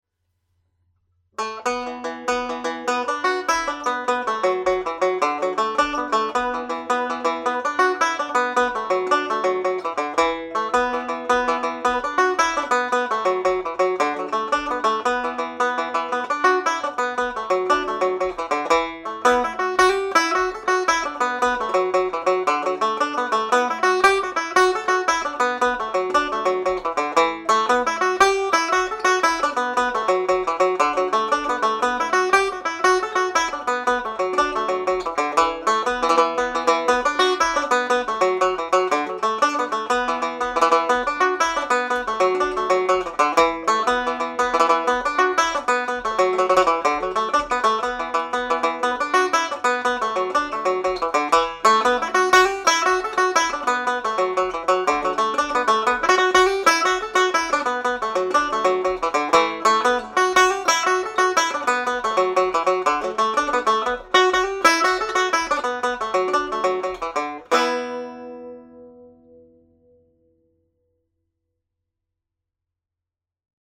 Pure Banjo » Videos
The Killavil Jig played at jig speed